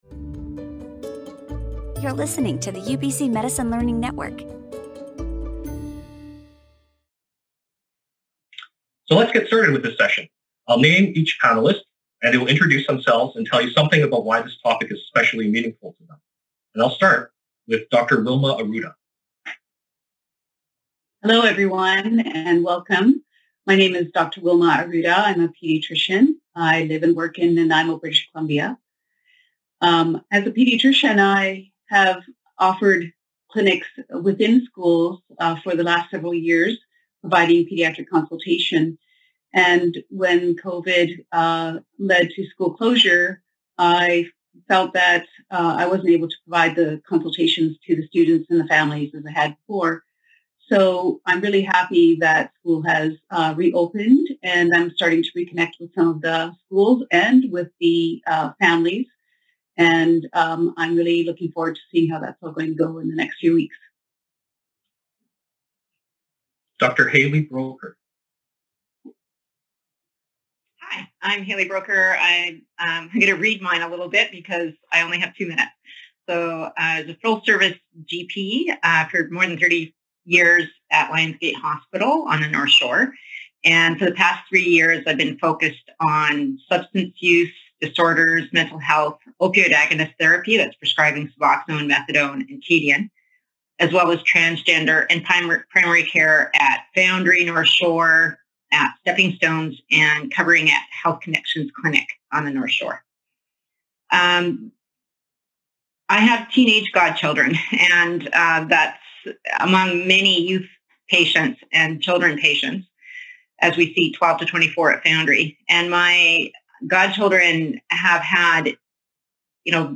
COVID-19 Updates brings together panels of health care professionals with expertise on the front lines of care to answer questions from colleagues about COVID-19 care.